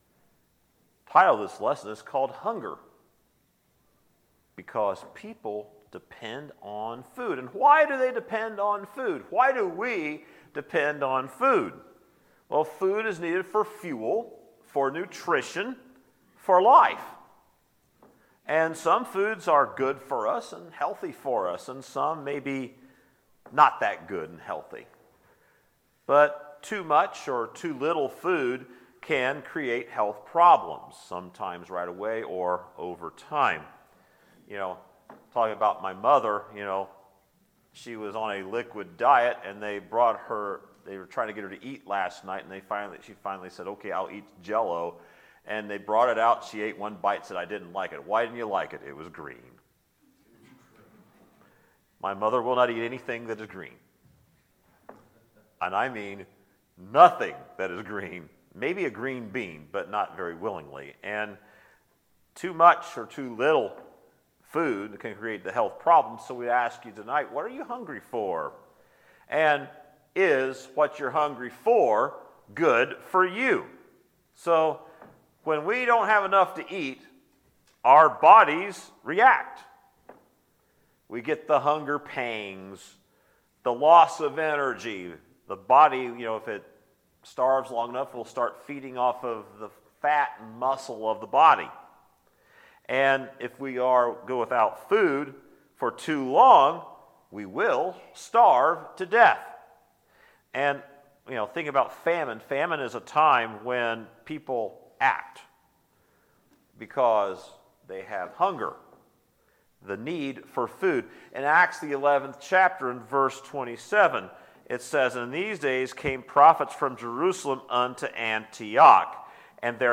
Sermons, March 8, 2020